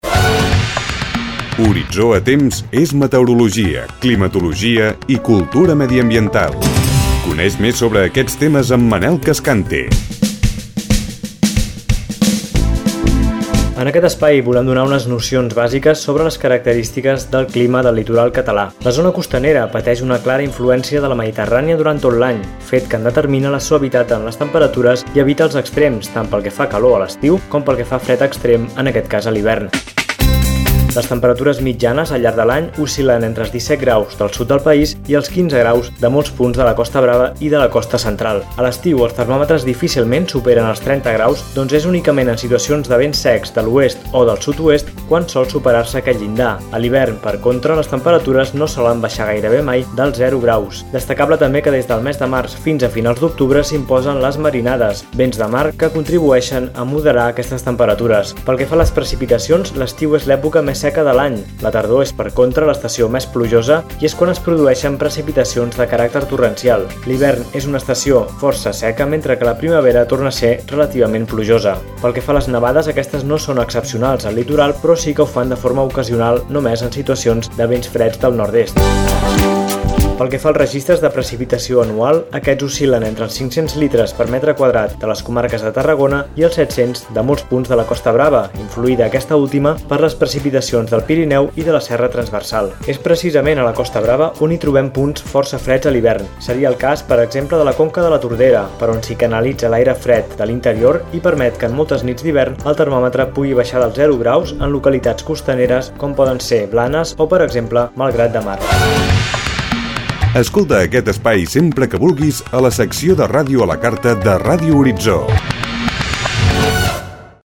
Careta i espai dedicat al clima al litoral català durant l'any
Divulgació